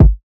SOUTHSIDE_kick_letspop.wav